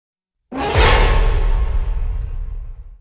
на смс
тревожные
страшные
жуткие
устрашающие
Пугающее смс на хэллоуин ;)